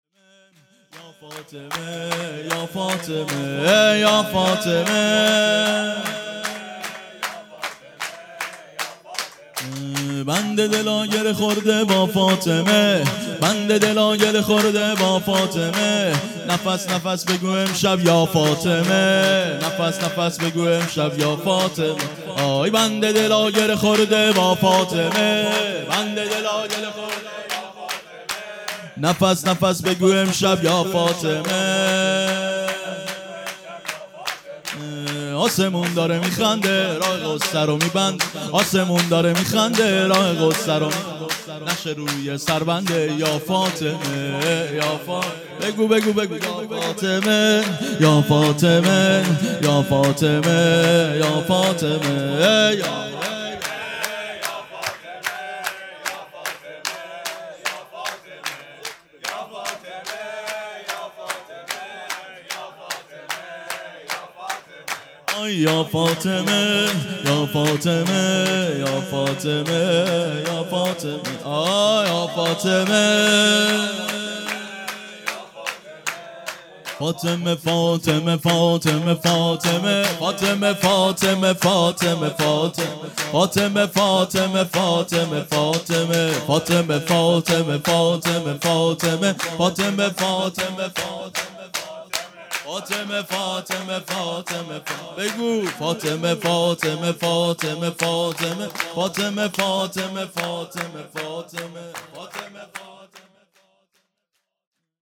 هیئت ثارالله شهرستان رودسر
ولادت حضرت فاطمه زهرا سلام الله علیها